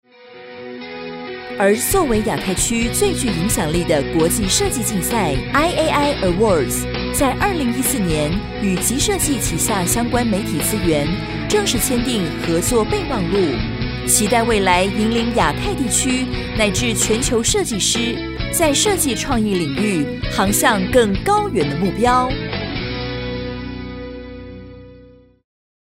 國語配音 女性配音員
她的聲線細緻，能夠以真摯的情感呈現角色內心的複雜性，無論是溫暖的母親形象還是堅韌的女主角，都表現得入木三分。
她能輕鬆駕馭多種語氣，包括溫柔感性、活潑幽默、專業正式，成為各種配音場景的不二之選。